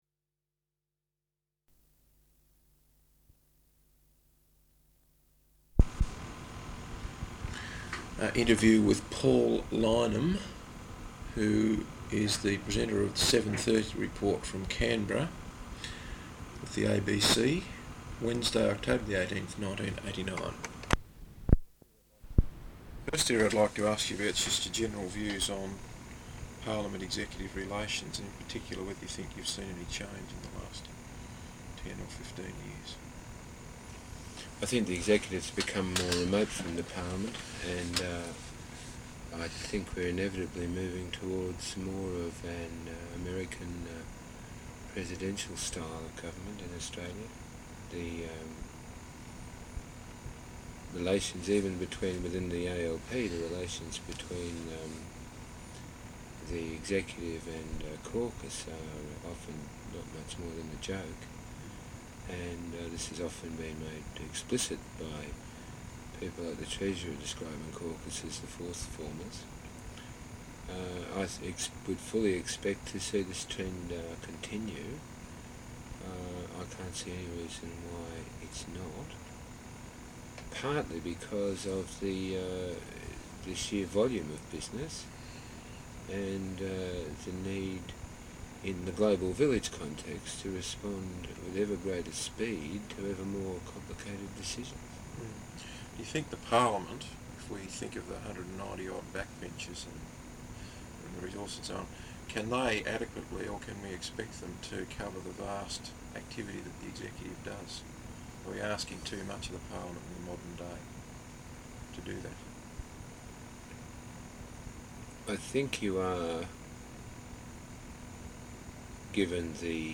Interview with Paul Lyneham, the presenter of the Seven Thirty Report from Canberra with the ABC, Wednesday, 18 October, 1989.